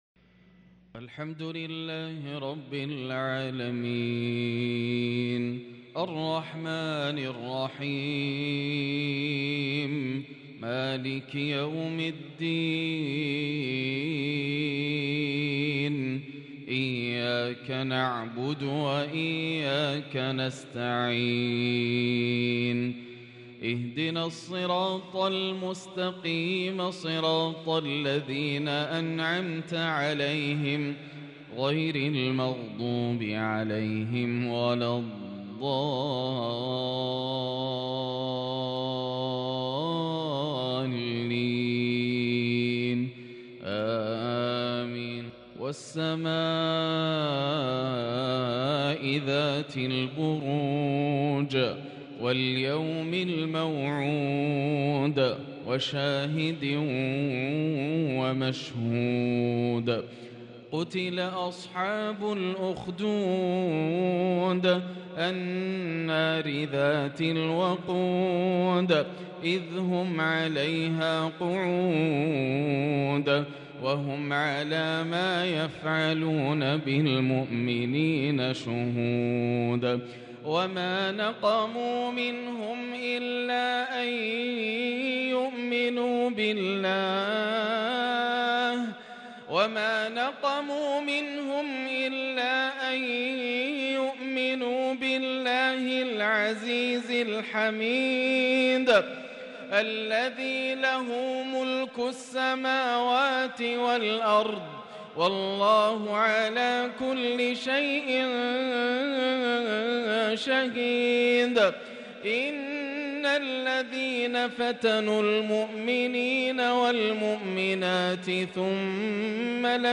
تلاوة مميزة لسورة البروج - مغرب الثلاثاء 7-4-1444هـ > عام 1444 > الفروض - تلاوات ياسر الدوسري